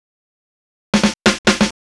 Fill 128 BPM (17).wav